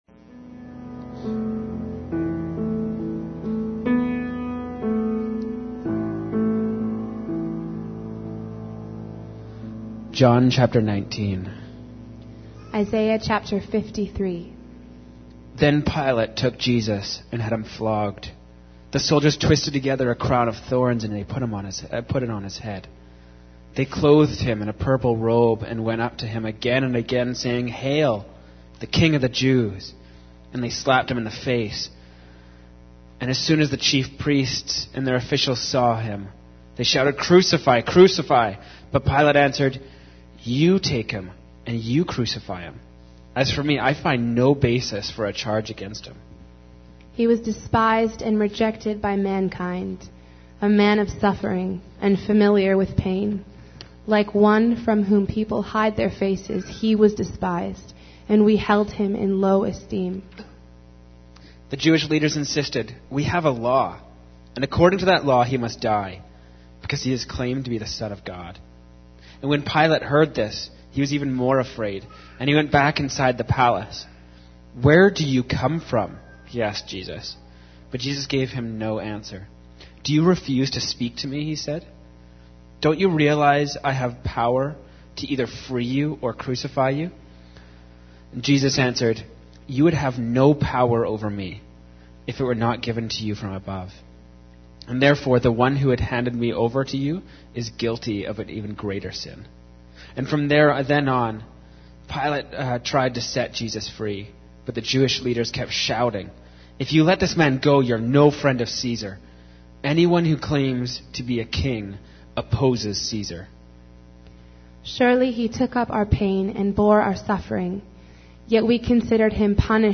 Sermons | North Shore Alliance Church